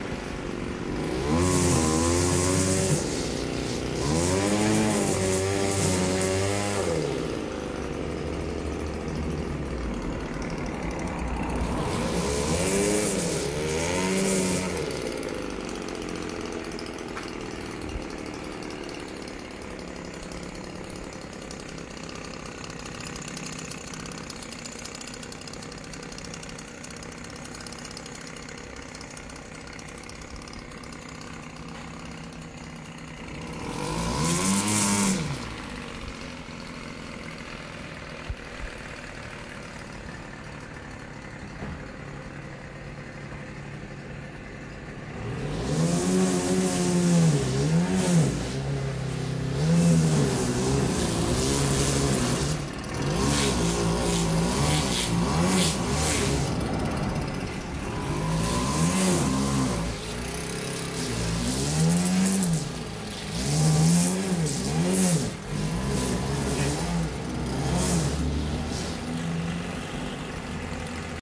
用推式卷轴割草机修剪草坪
无需担心发动机噪音，只需在卷轴上转动刀片并切割草坪。
标签： 切割机 卷筒 没有 割草机 燃气 供电 割草机 修剪 草坪
声道立体声